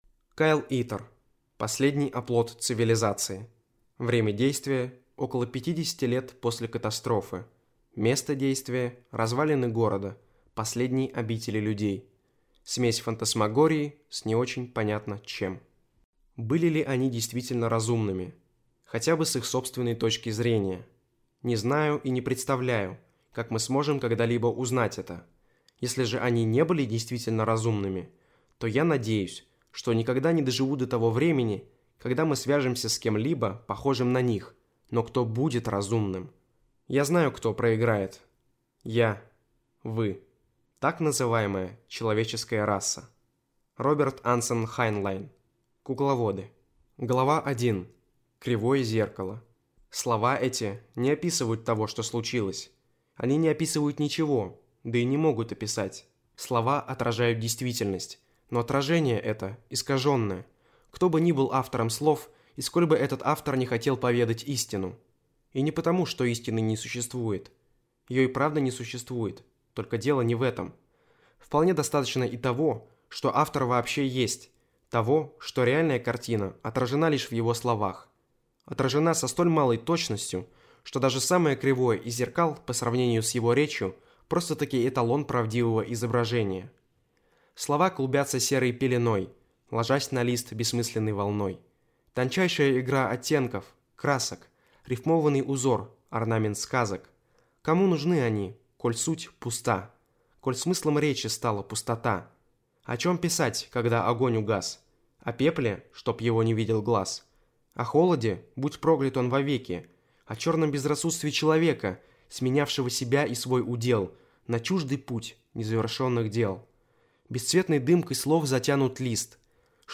Аудиокнига Последний оплот цивилизации | Библиотека аудиокниг